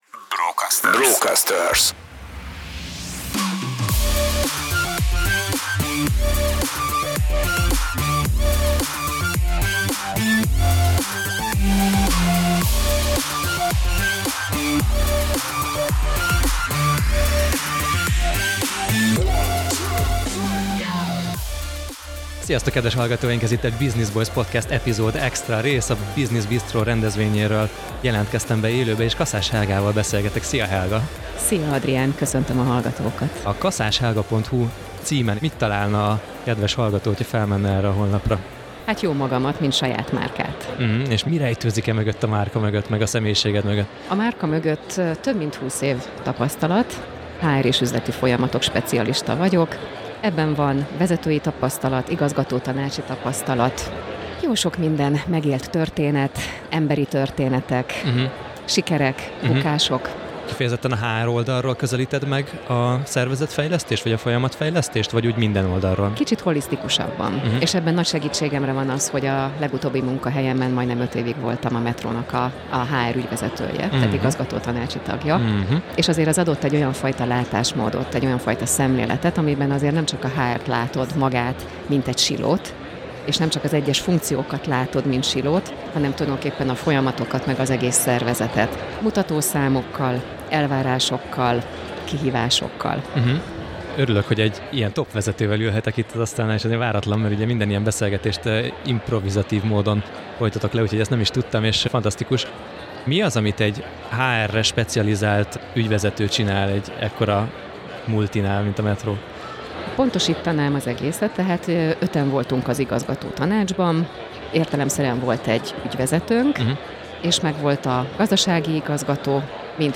Business Bistrobeszélgetés
Elolvasom Projekt Management Szövetség Tehetségmenedzsment és teljesítménymenedzsment a projektekben Elolvasom Business Bistro beszélgetés A Brocasterz pop-up stúdiójában, profi környezetben kipróbálni a mikrofon mögötti világot óriási élmény volt.